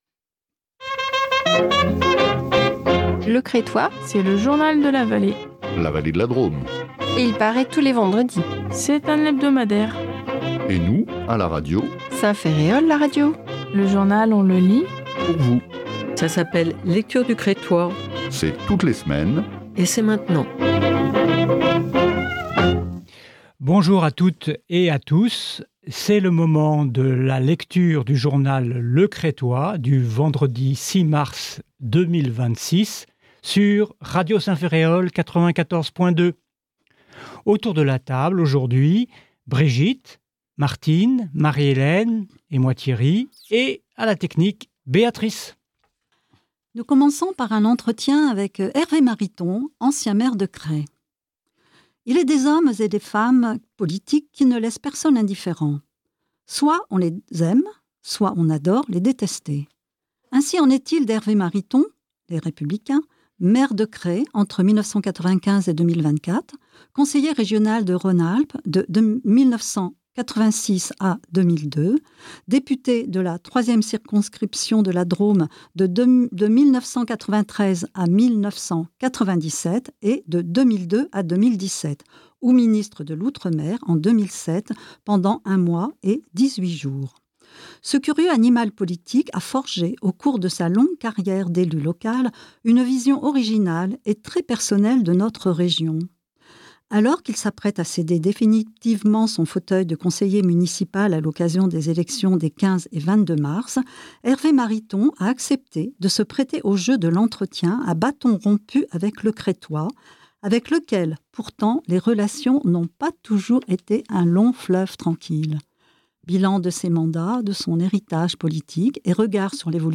Lecture du Crestois du 06 Mars 2026